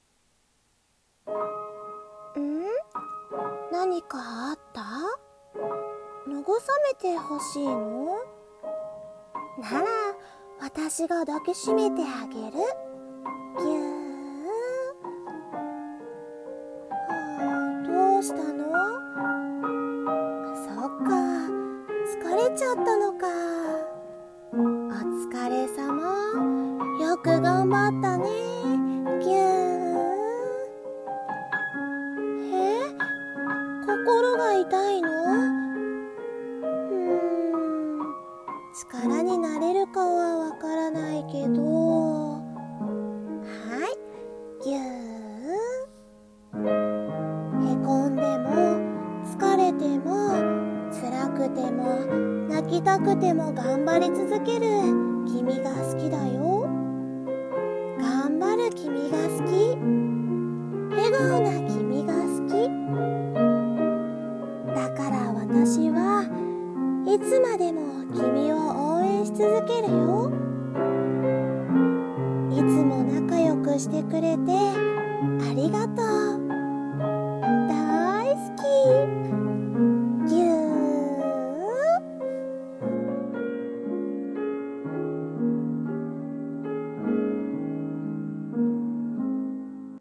【一人声劇】ぎゅー。